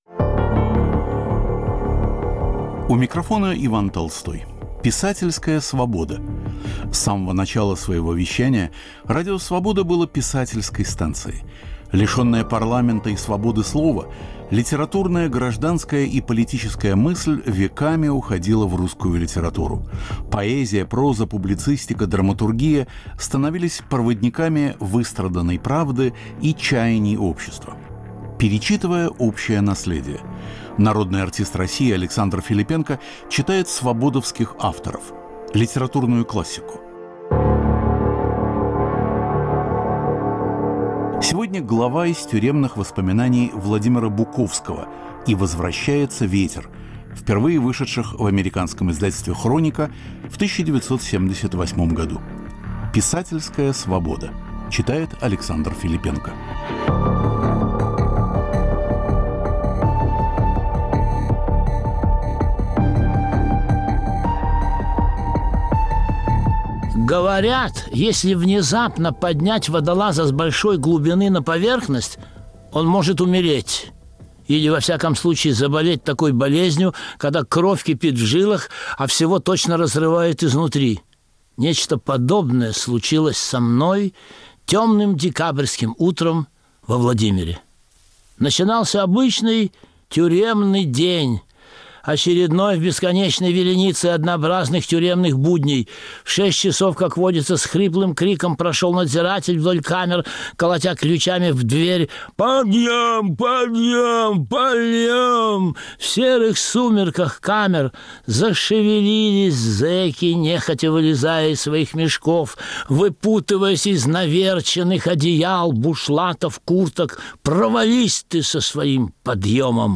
В нашем цикле "Писательская свобода" народный артист России Александр Филиппенко читает главу из воспоминаний Владимира Буковского "И возвращается ветер".